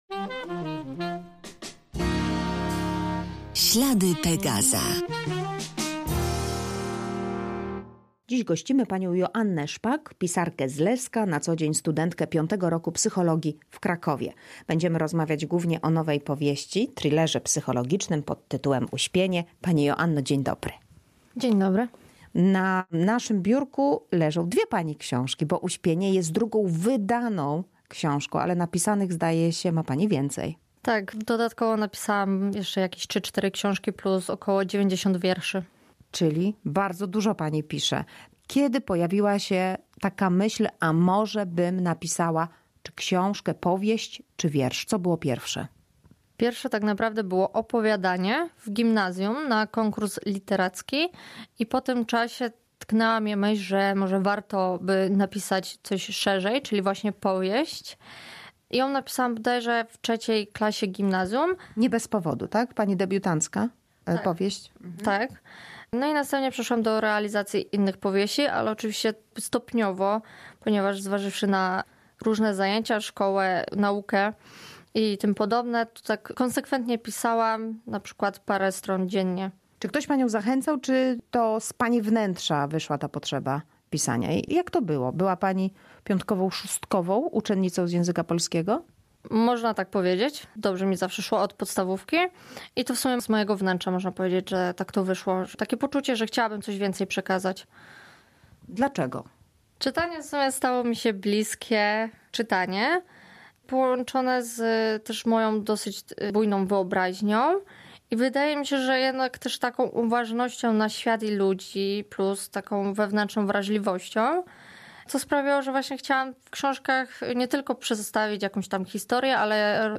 Rozmowa o nowej powieści i pisarstwie